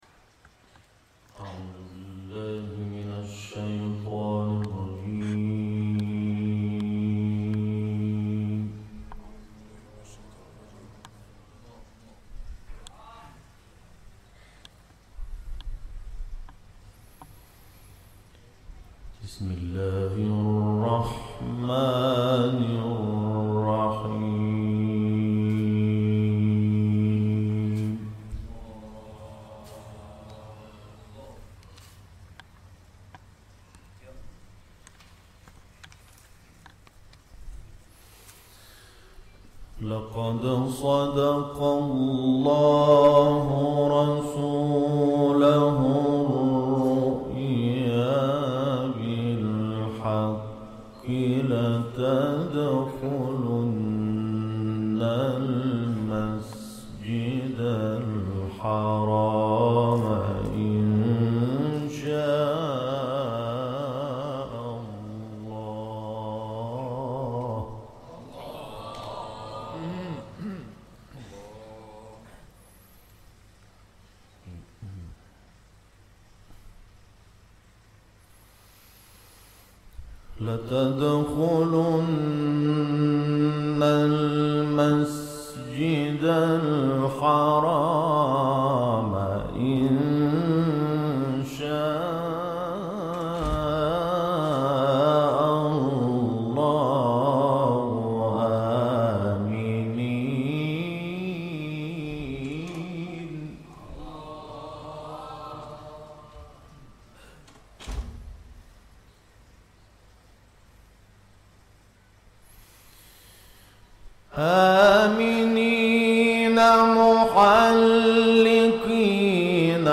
ইন্দোনেশিয়ায় মিশরের প্রসিদ্ধ ক্বারীর তিলাওয়াত
আন্তর্জাতিক ডেস্ক: সম্প্রতি মিশরের প্রসিদ্ধ ক্বারি মাহমুদ শাহাত আনওয়ার ইন্দোনেশিয়ায় এক কুরআন মাহফিলে কুরআন তিলাওয়াত করেছেন।